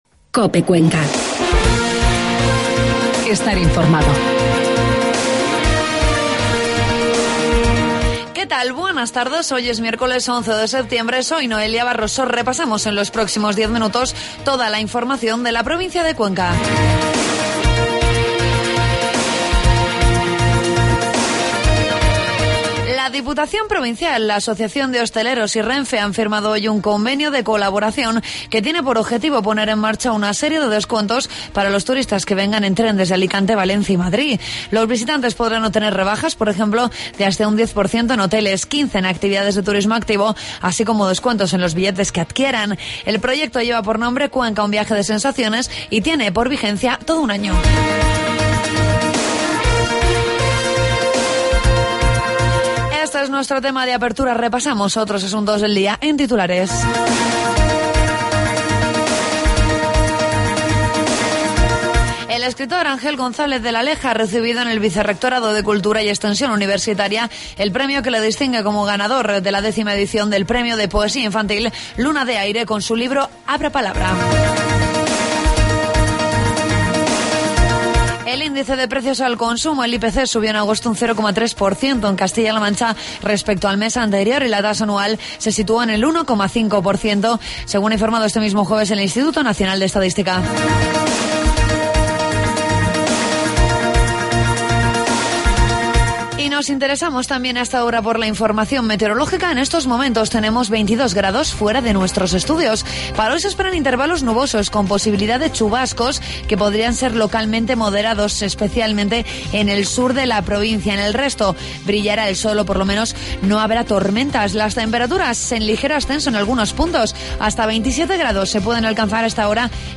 Redacción digital Madrid - Publicado el 16 sep 2013, 09:25 - Actualizado 14 mar 2023, 09:28 1 min lectura Descargar Facebook Twitter Whatsapp Telegram Enviar por email Copiar enlace Toda la información de la provincia de Cuenca en los informativos de mediodía de COPE